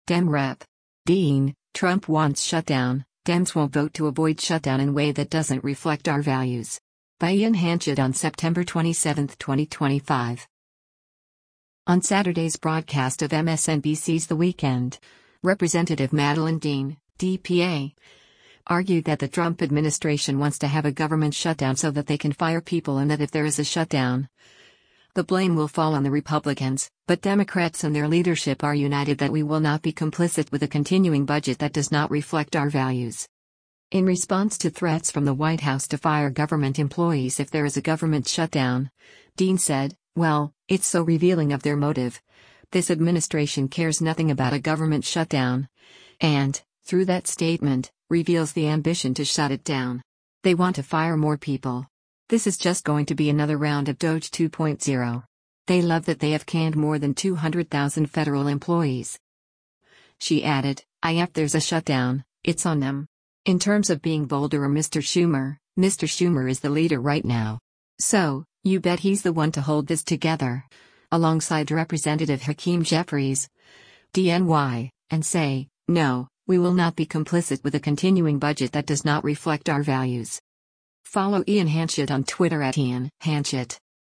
On Saturday’s broadcast of MSNBC’s “The Weekend,” Rep. Madeleine Dean (D-PA) argued that the Trump administration wants to have a government shutdown so that they can fire people and that if there is a shutdown, the blame will fall on the Republicans, but Democrats and their leadership are united that “we will not be complicit with a continuing budget that does not reflect our values.”